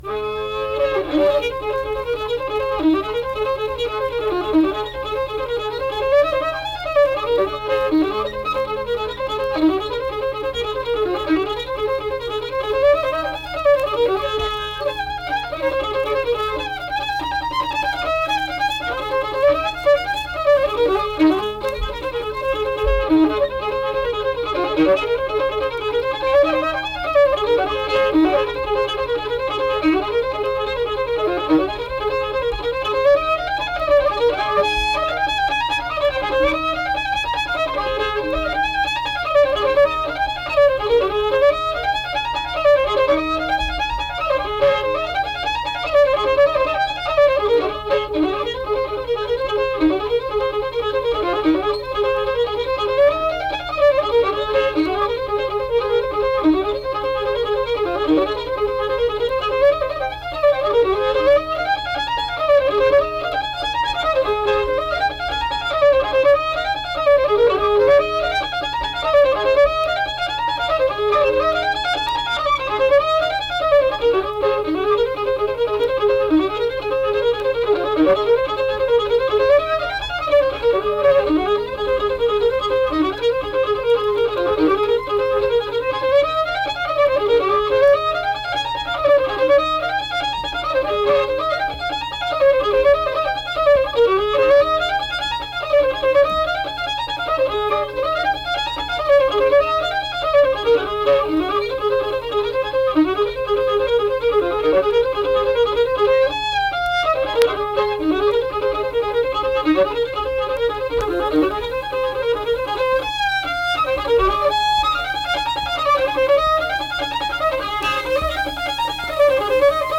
Brickyard Joe - West Virginia Folk Music | WVU Libraries
Unaccompanied fiddle music and accompanied (guitar) vocal music
Instrumental Music
Fiddle